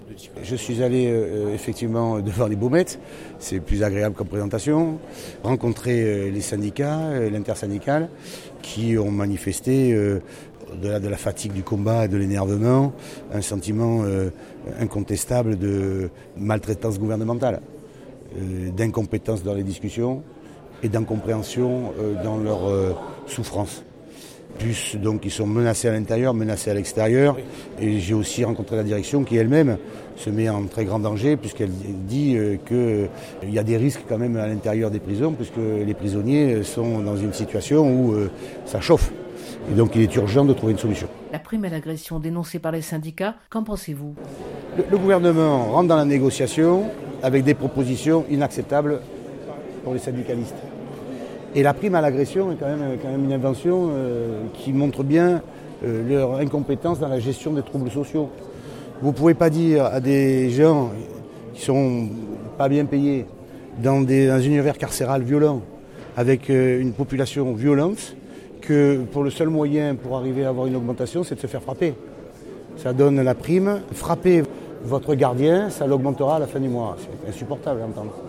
Les journalistes étaient présents en nombre pour les vœux à la presse de Renaud Muselier.